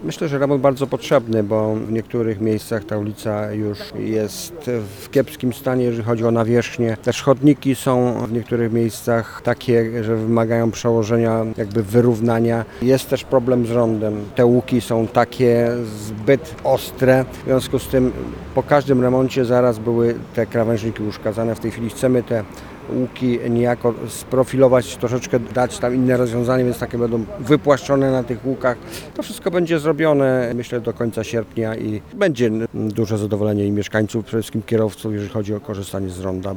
Mówi starosta mielecki Stanisław Lonczak.